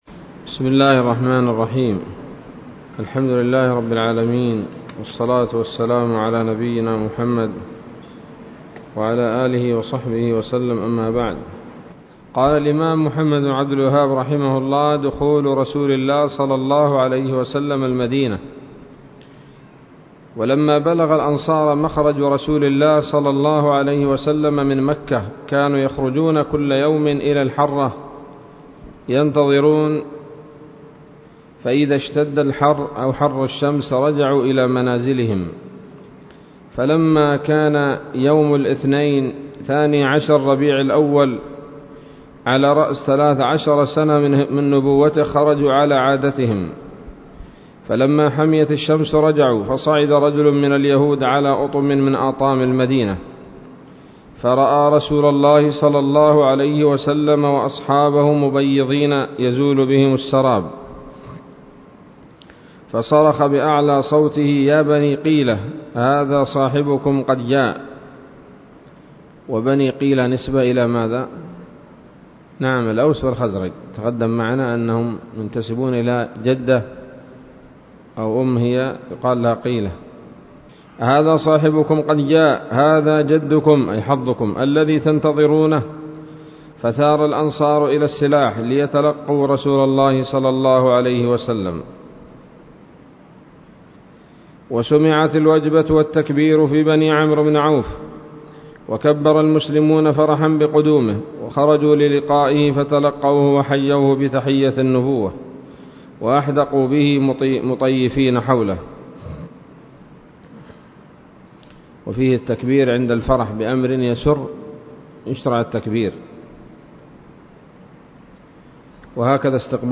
الدرس الحادي والثلاثون من مختصر سيرة الرسول ﷺ